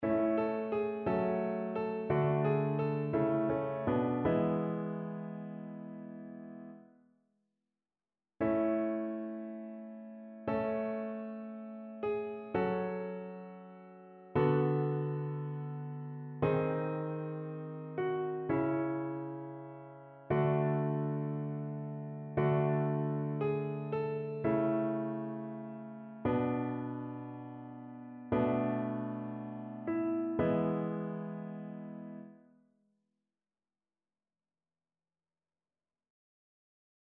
Chœur
annee-c-temps-ordinaire-19e-dimanche-psaume-32-satb.mp3